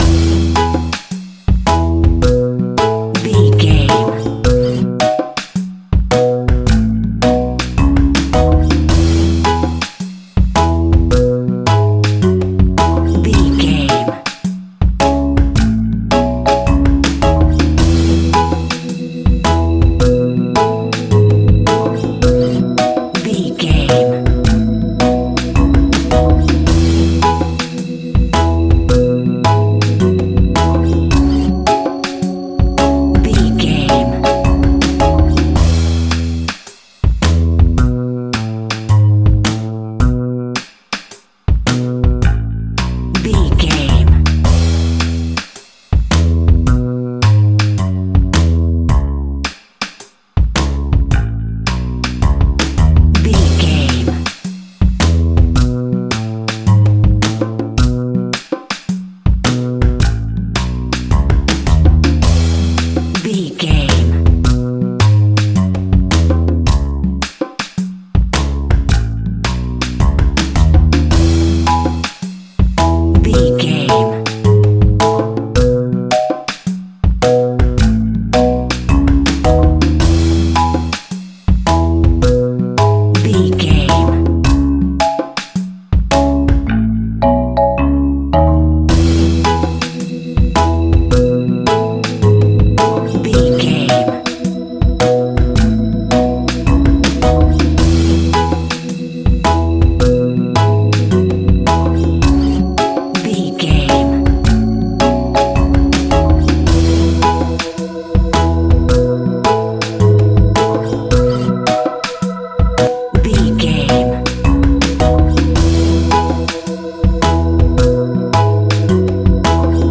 A summer time Reggae Track.
Ionian/Major
Caribbean
tropical